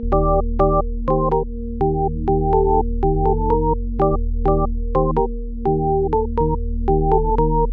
标签： 124 bpm Trap Loops Synth Loops 1.30 MB wav Key : Unknown
声道立体声